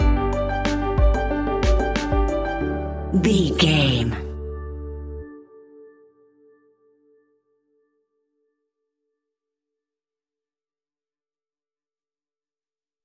Ionian/Major
D
pop rock
indie pop
fun
energetic
instrumentals
upbeat
groovy
guitars
bass
drums
piano
organ